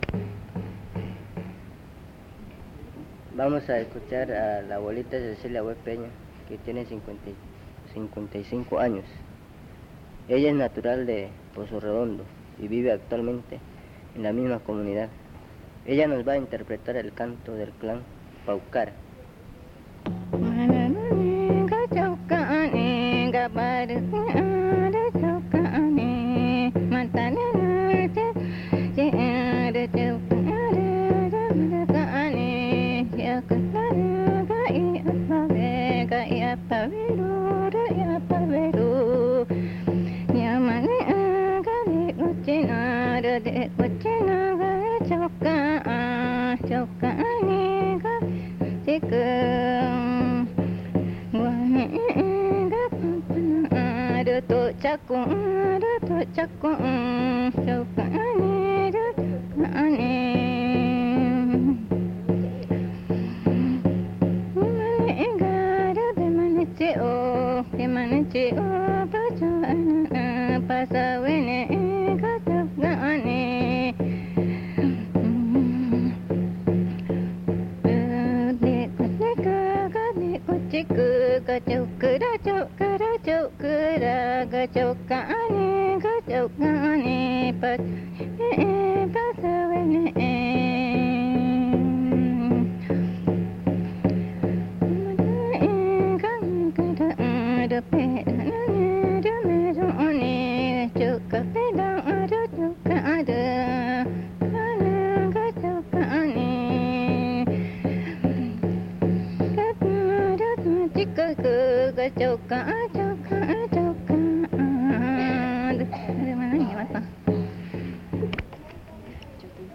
Canto del clan Paucara
Pozo Redondo, Amazonas (Colombia)